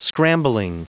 Prononciation du mot scrambling en anglais (fichier audio)
Prononciation du mot : scrambling